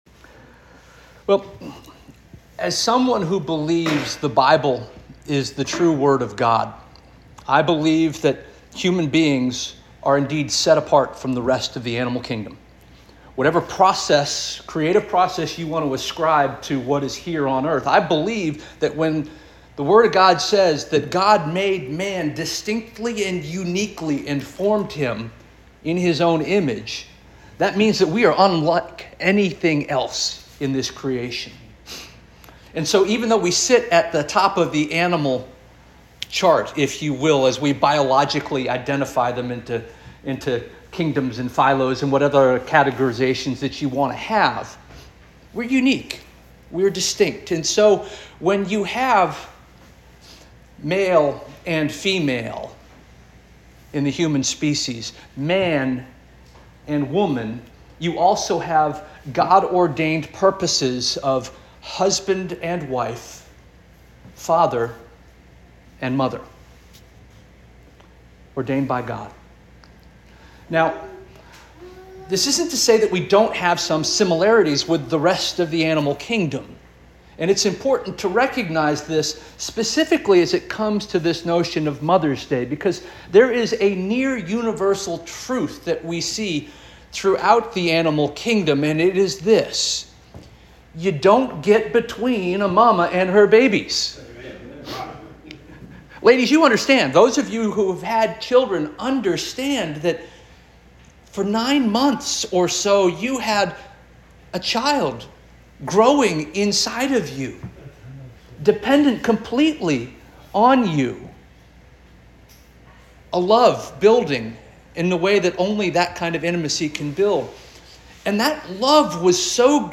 May 11 2025 Sermon - First Union African Baptist Church